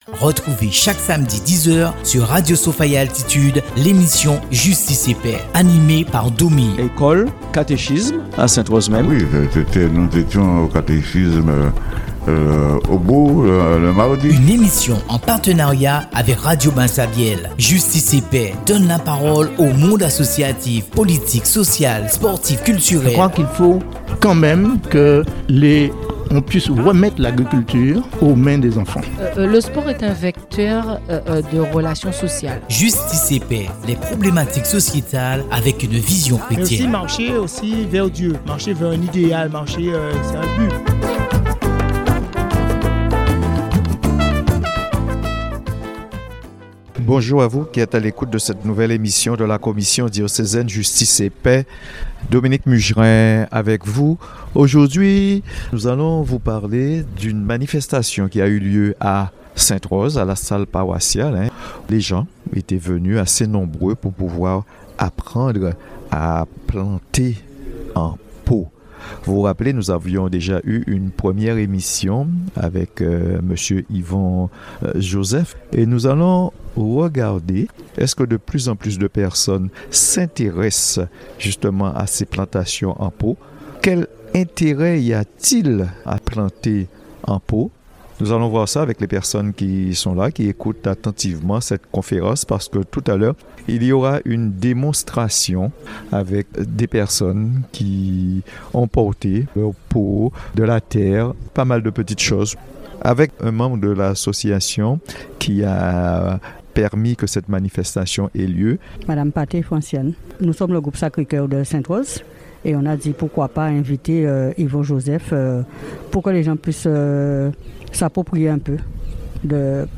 La salle paroissiale était remplie, un véritable succès...